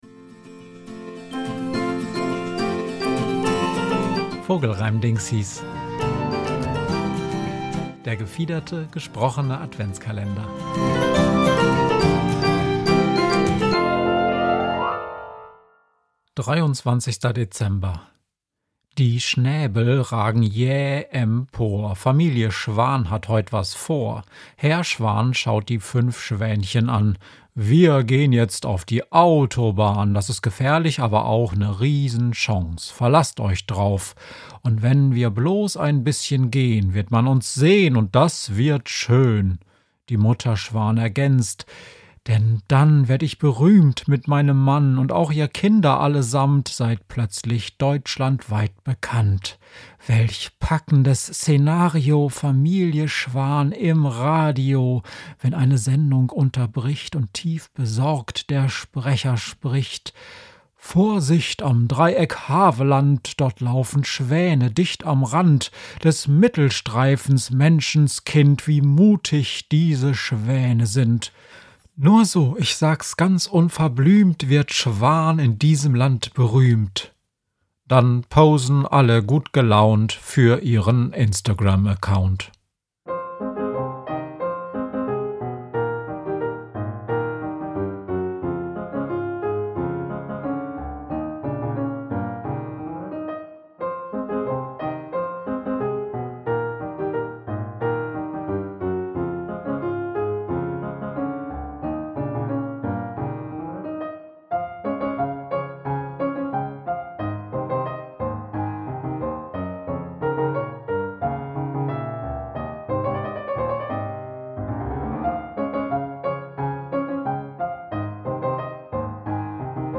Vogelreimdingsis ist der gefiederte, gesprochene Adventskalender